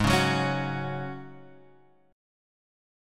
G# 7th Suspended 4th